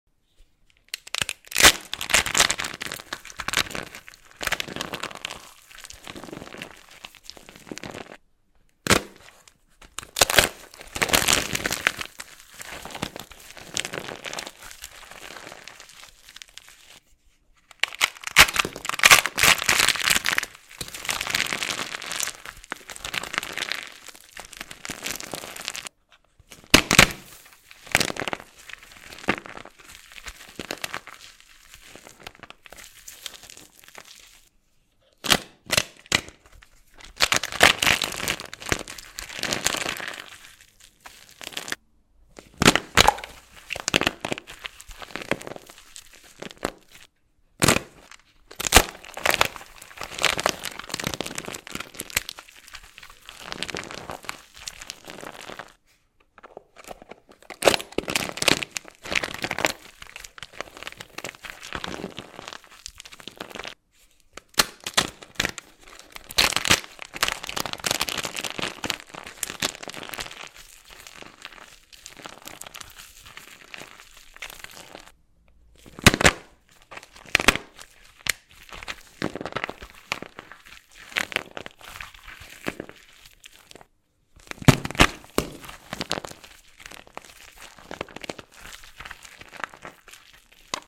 ASMR soap